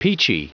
Prononciation du mot peachy en anglais (fichier audio)
Prononciation du mot : peachy